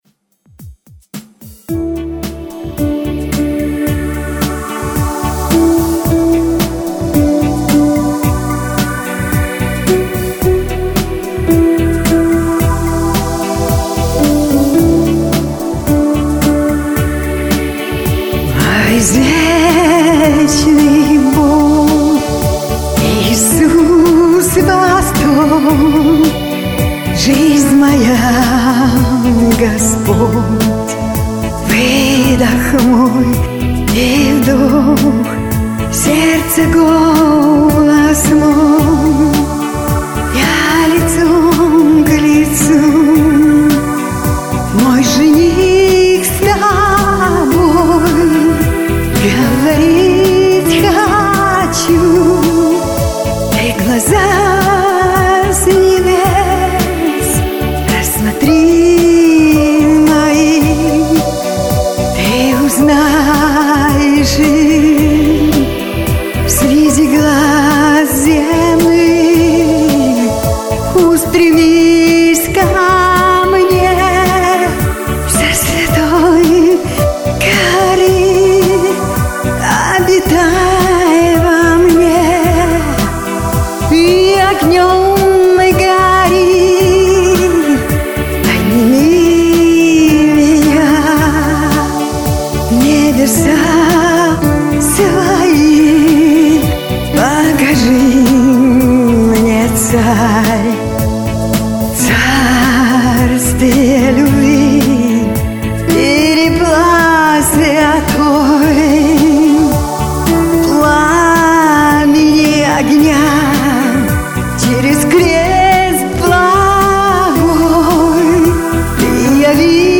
Христианская песня
(ремикс)